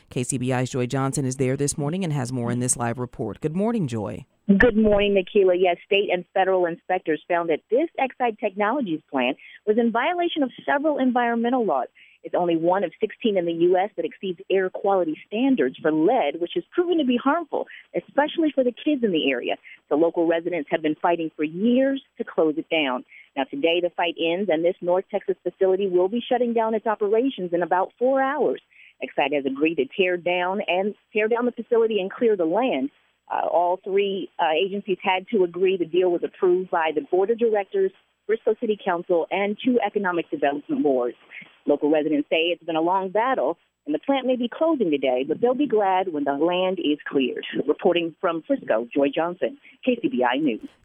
Zig Ziglar/Zig Ziglar Inc. - Live Coverage - 11/29/12